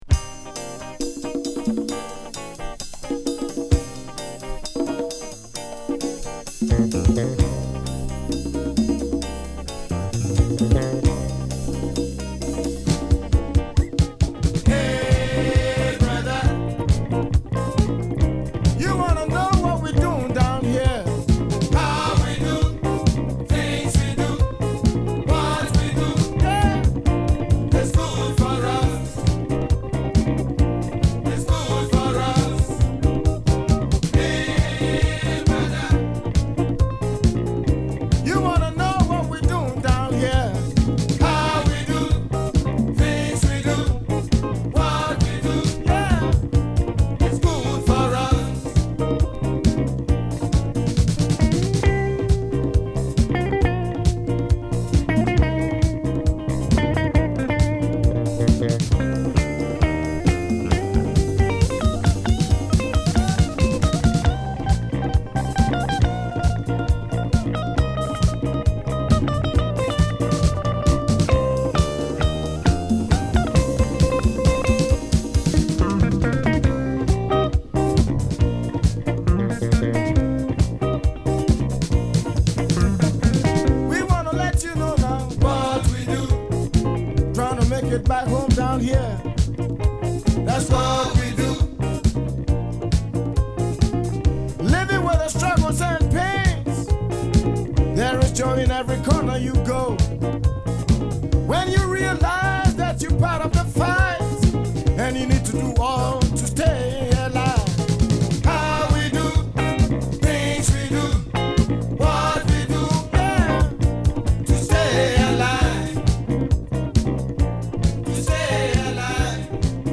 強烈なグルーヴに圧倒されるディープ・オーガニックなアフロ・ジャズ・ファンク名盤！